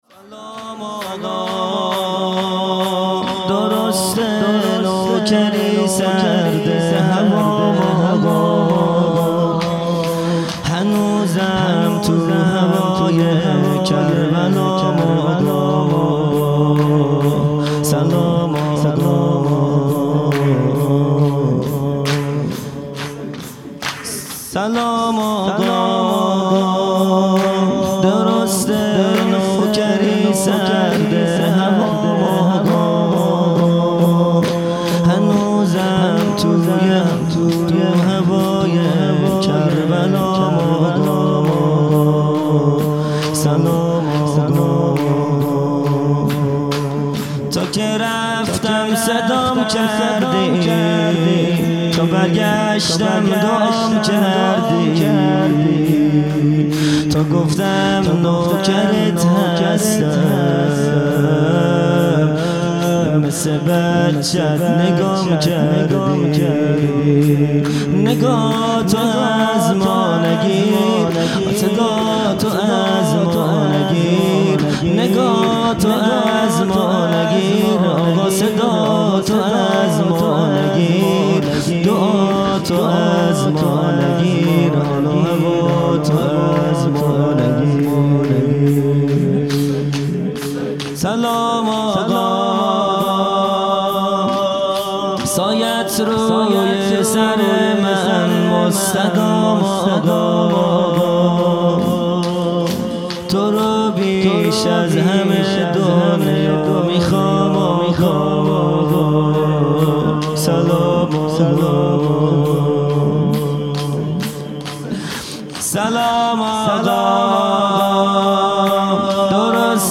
خیمه گاه - هیئت بچه های فاطمه (س) - شور | سلام آقا
جلسۀ هفتگی | شهادت حضرت رقیه(س)